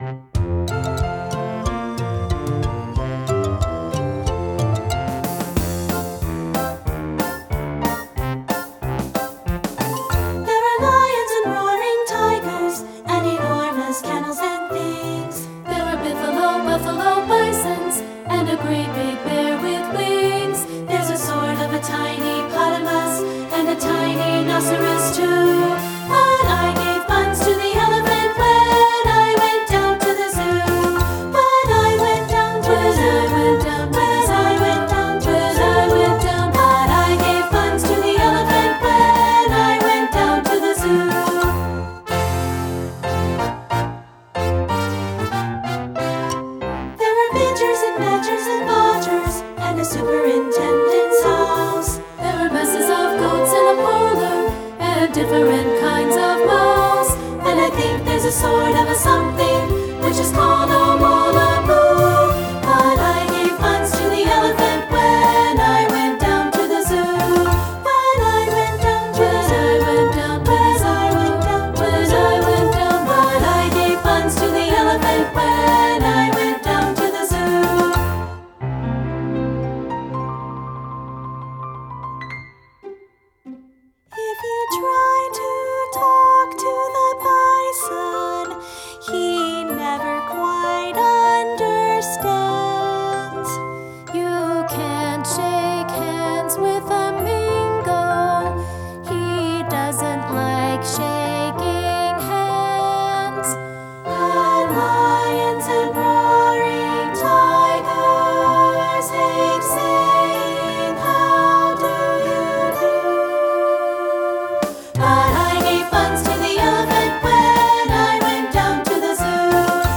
Voicing